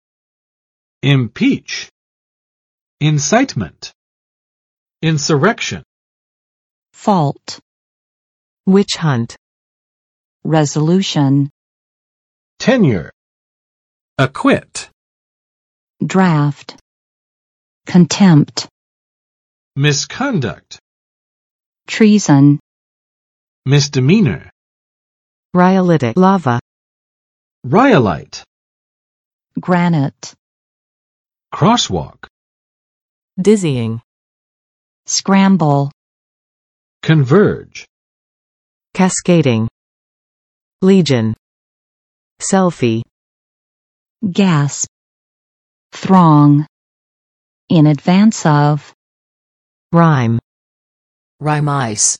[ɪmˋpitʃ] v.【主美】弹劾